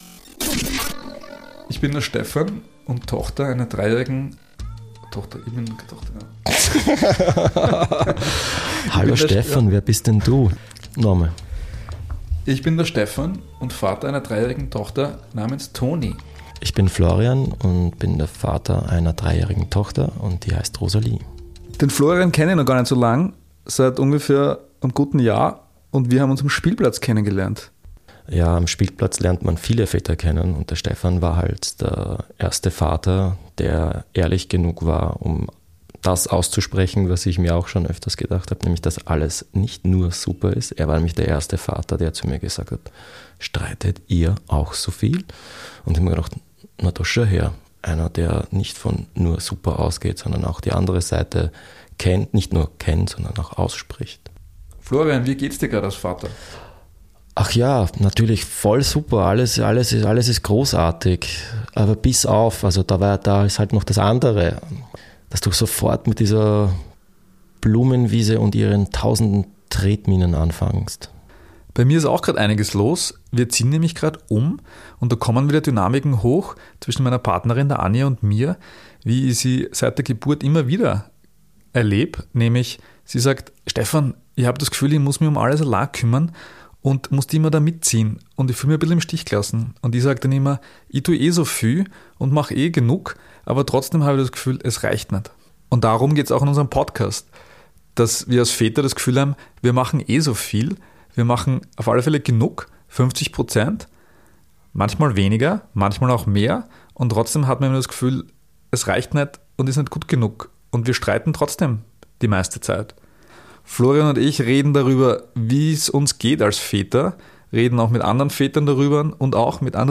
Trailer: Wie geht's dir als Vater?